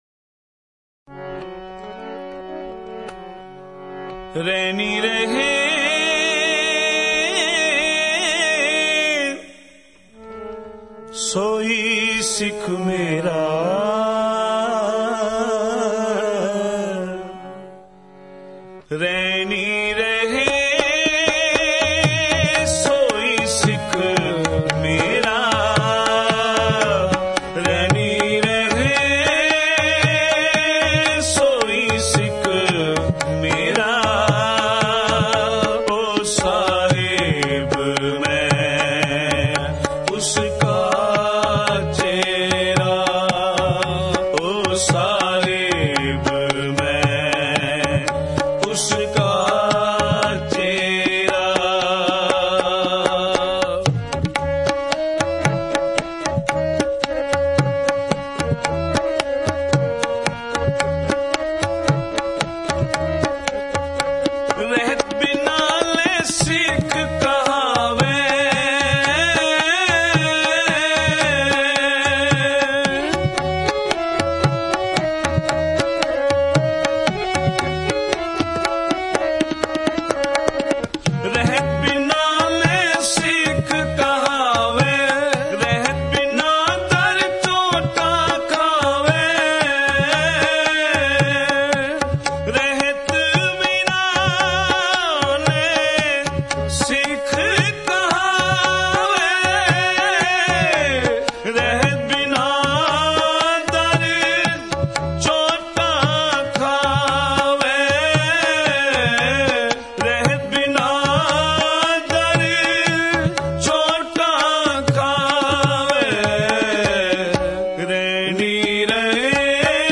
Genre: Shabad Gurbani Kirtan Album Info